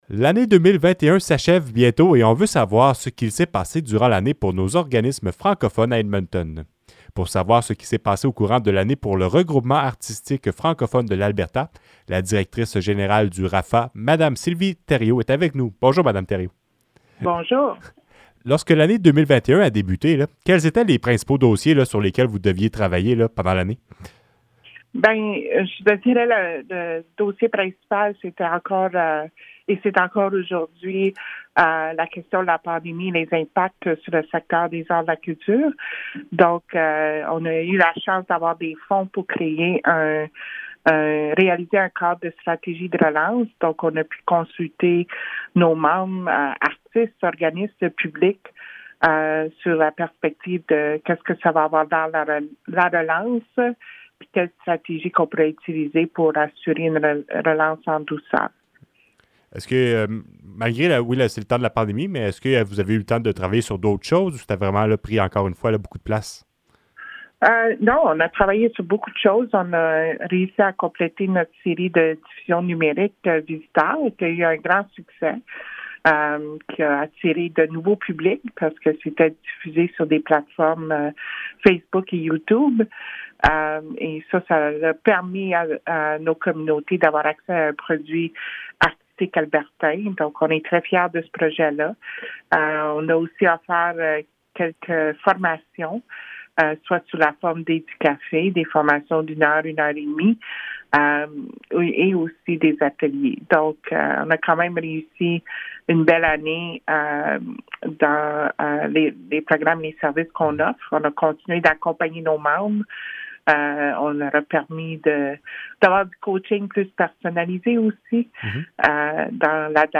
Entrevue-Bilan-RAFA.mp3